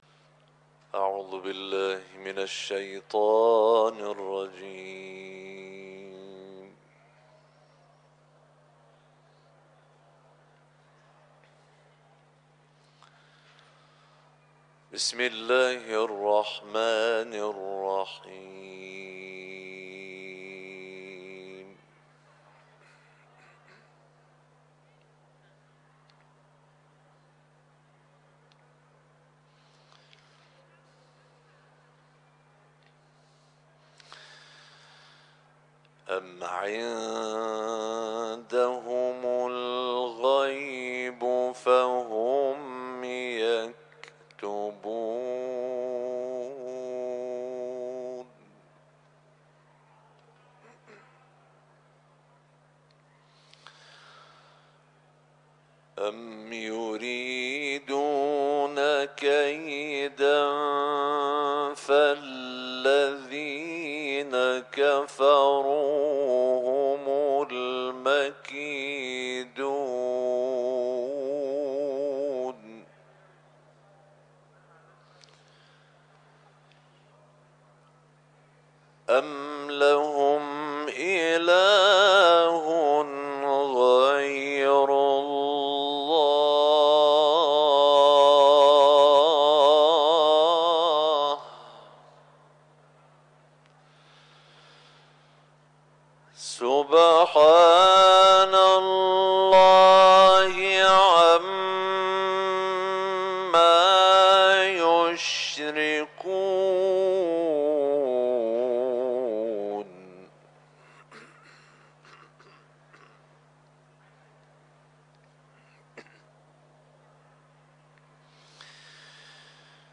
تلاوت
سوره نجم ، سوره طور ، حرم مطهر رضوی